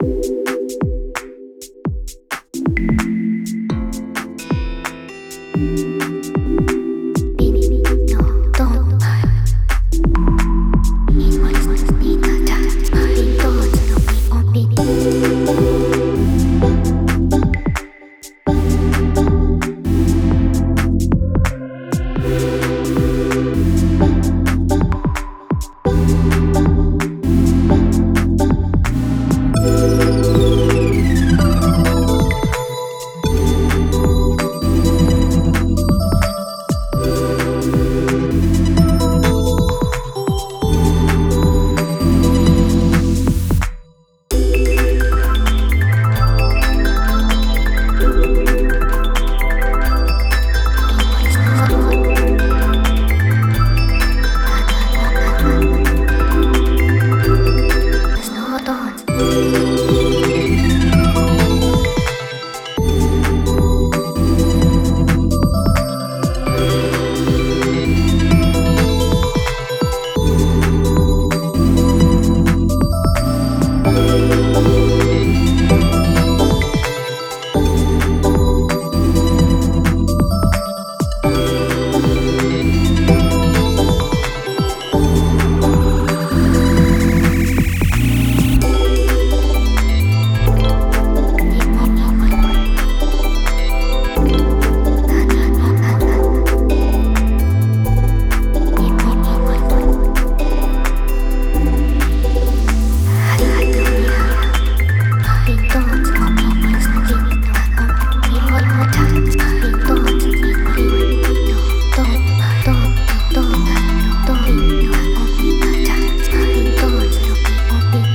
◆ジャンル：Electronic/エレクトロニック
途中の「パっ！」というスキャットは先に説明したHarmorです。